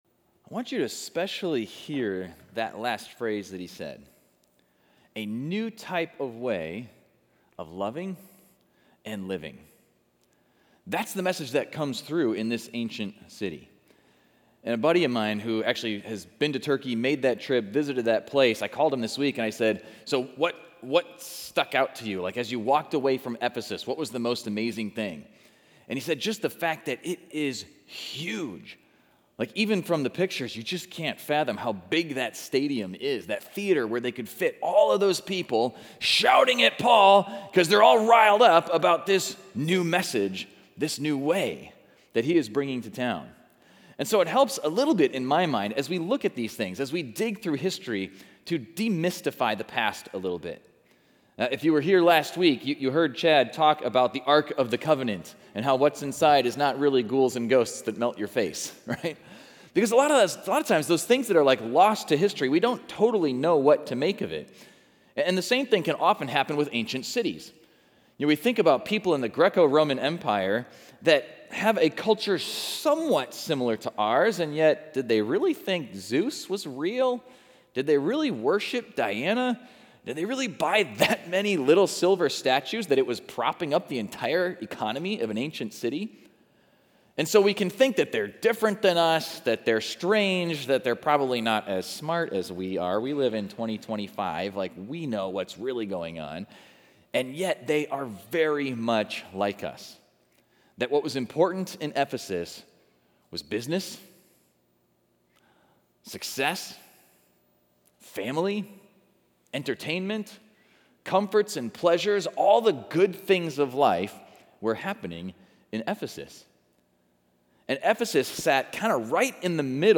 Exploring Service / Raiders of The Lost Cities / Ephesus